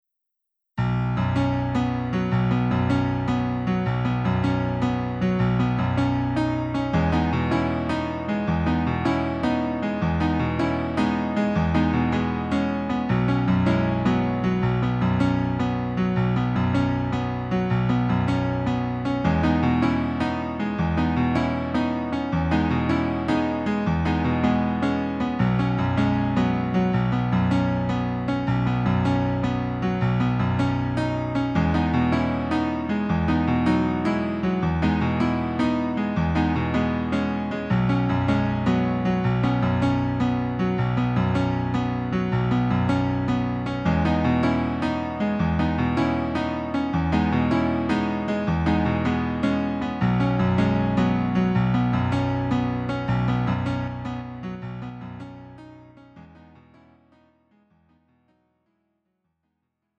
음정 원키 3:19
장르 가요 구분 Lite MR
Lite MR은 저렴한 가격에 간단한 연습이나 취미용으로 활용할 수 있는 가벼운 반주입니다.